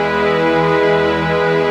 Pad Emaj 02.wav